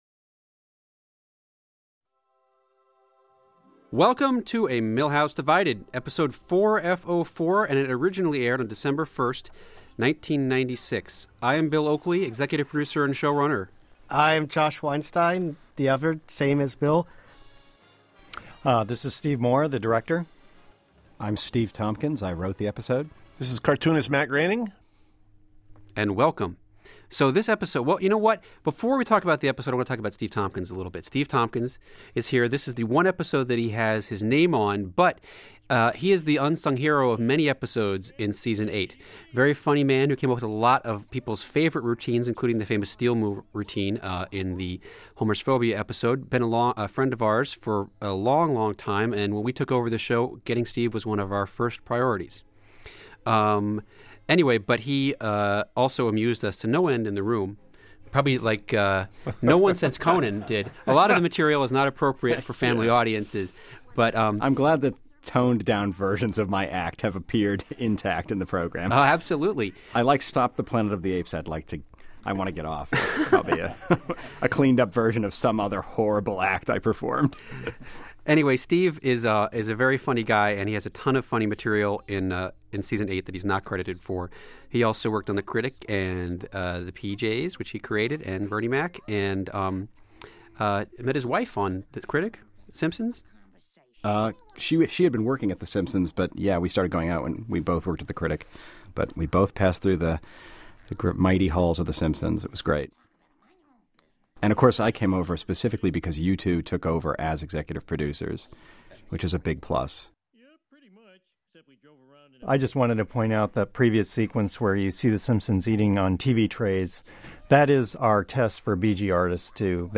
52e9a19dab75 TV/the Simpsons/Season 08/Commentary